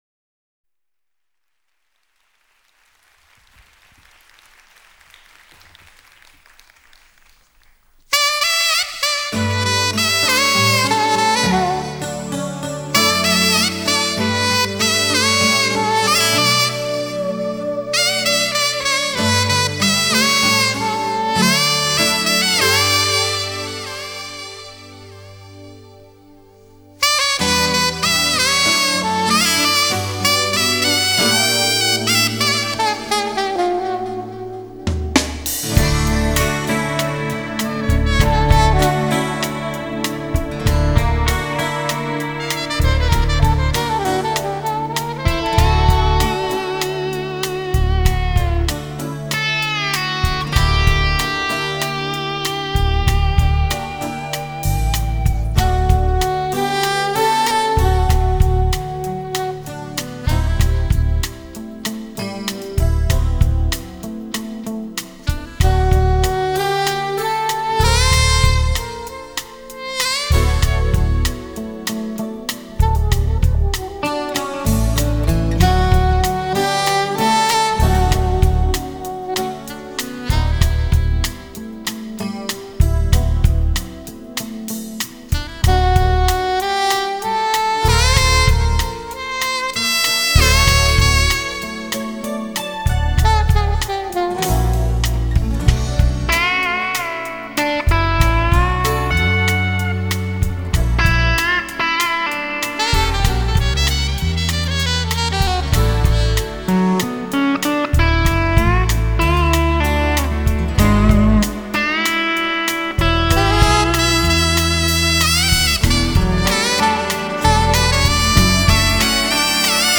13th live